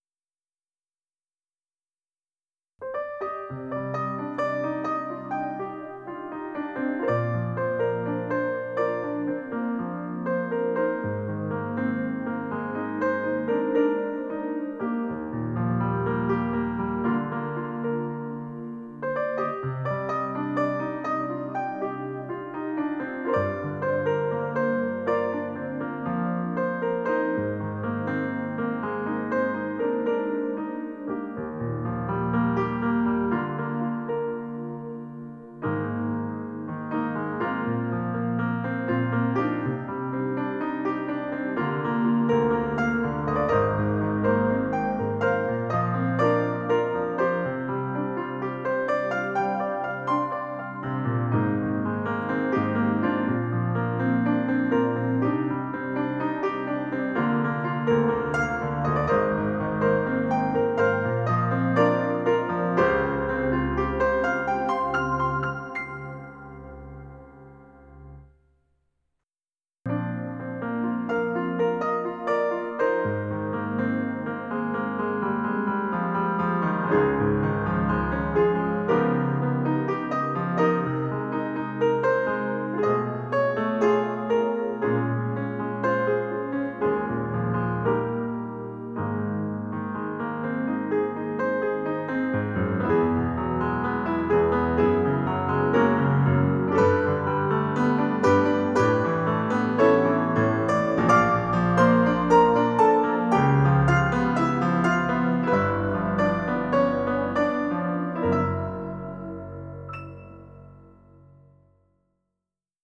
Die Pop-Ballade „Schimmernde Träume“ wirft den Blick auf einen abendlichen Sternhimmel, der funkelt und leuchtet, wie der ferne Traum am Horisont. Die erzählerische Art der Musik verbindet uns eng mit der inneren Gefühlswelt und zieht die Zuhörer auf den Pfad der Leidenschaft, der Sehnsucht, sowie der Faszination der Liebe.